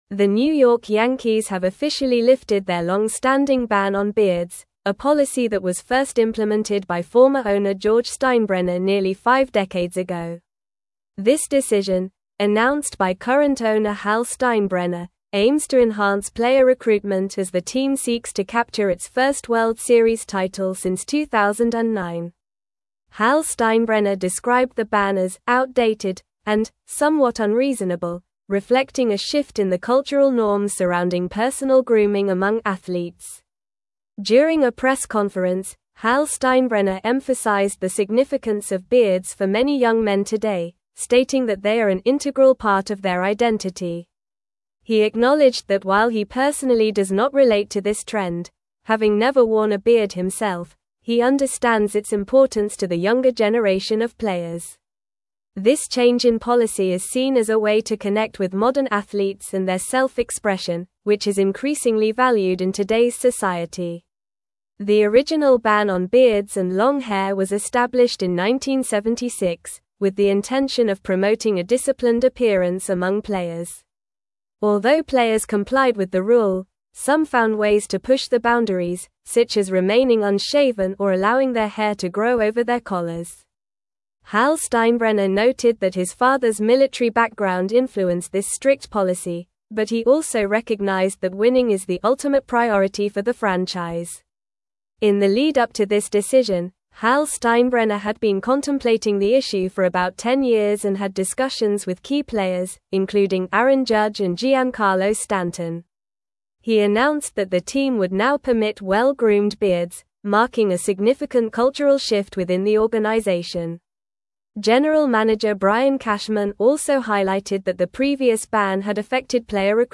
Normal
English-Newsroom-Advanced-NORMAL-Reading-Yankees-Lift-Longstanding-Beard-Ban-for-Players.mp3